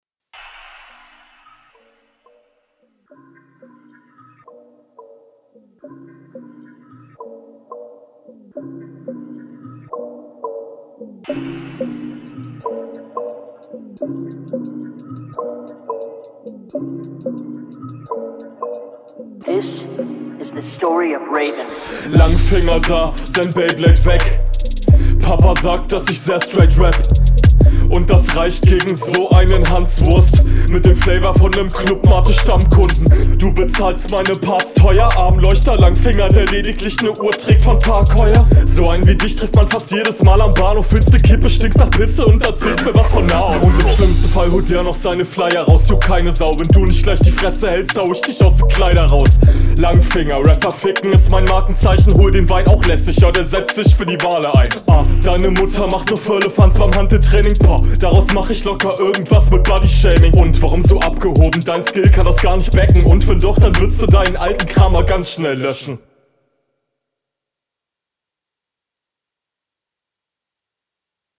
geiler beat flow hat nicen style clubmate zeile fand ich sehr passend die meisten lines …